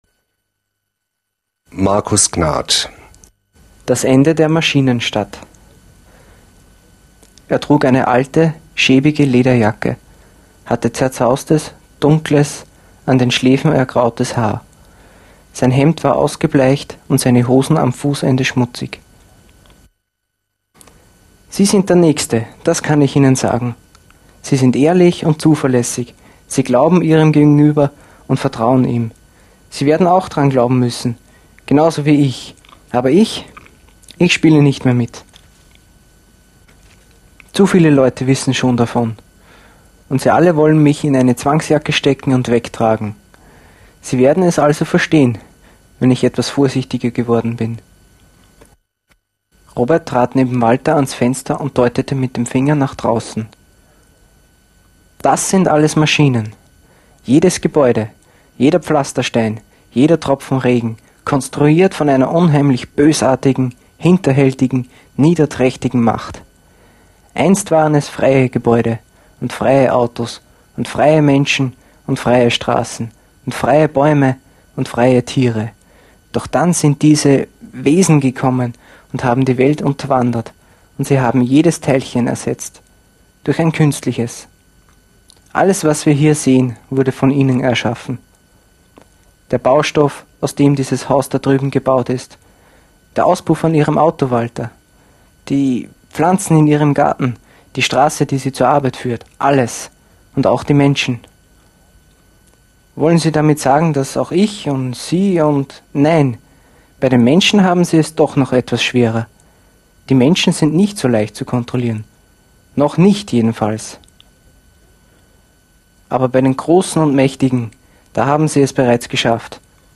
Autorenstimmen sind sehr sprechend.
Unsere Quellen sind vor allem das kulturfreundliche und werbefreie Deutschlandradio mit seinen Lesereihen und das ehemalige BREMEN 2, heute Nordwestradio.